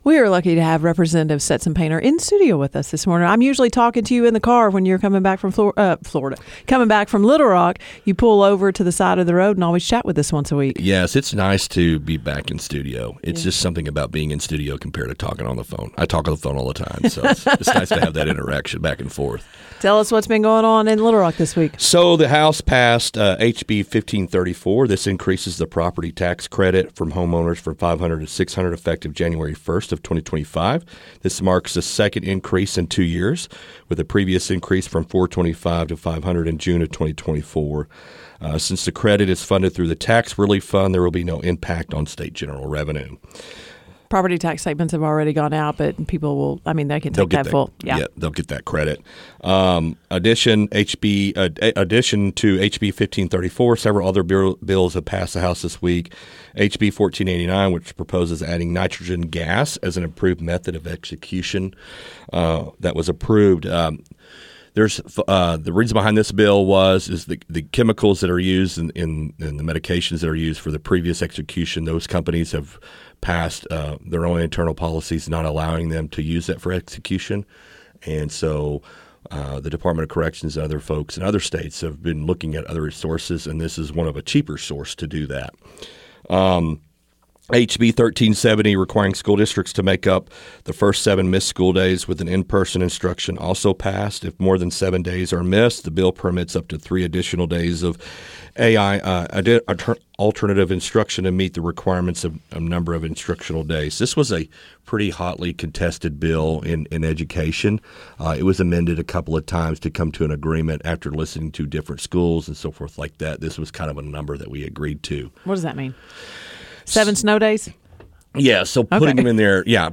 District 3 Arkansas State Representative Stetson Painter spoke with KTLO, Classic Hits and The Boot News and shares some of the bills the House passed this week.
FULL INTERVIEW